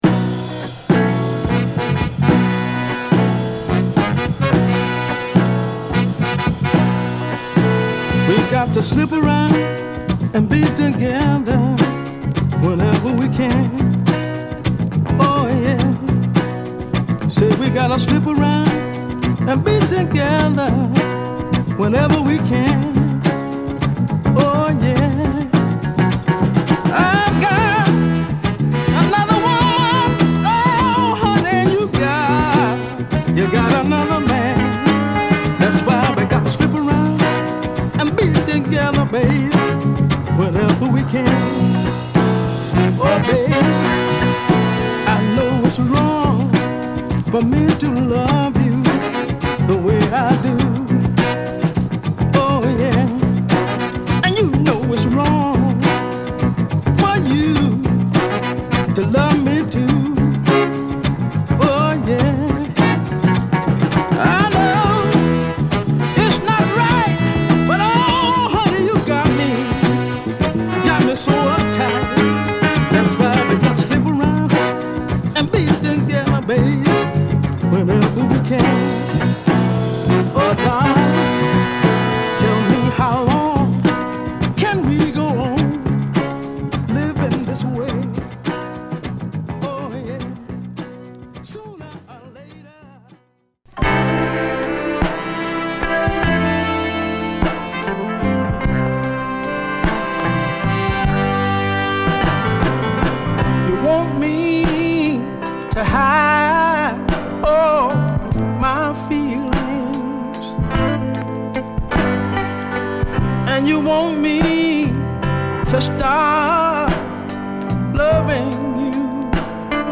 両面共地味渋でオススメ。